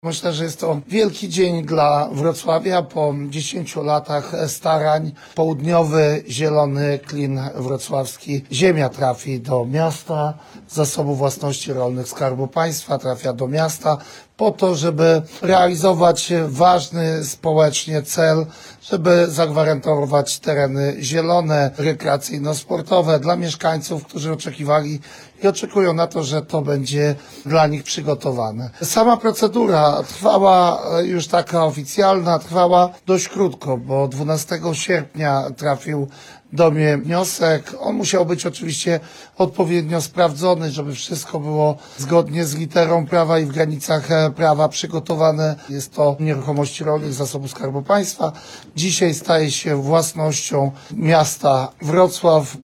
Po wielu latach starań miejscowych działaczy i władz miasta Skarb Państwa przekazał Miastu Wrocław działkę, na której zostanie utworzony Zielony Klin Południa Wrocławia. Mówi Stefan Krajewski, Minister Rolnictwa i Rozwoju Wsi.
01_Minister-Rolnictwa-Stefan-Krajewski_setka.mp3